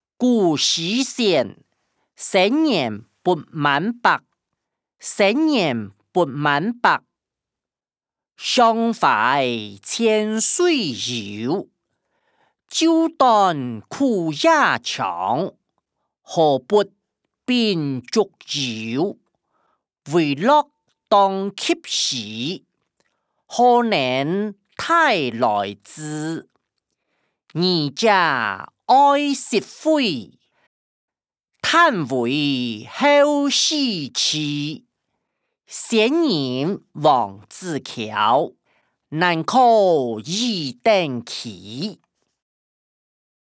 古典詩-生年不滿百音檔(大埔腔)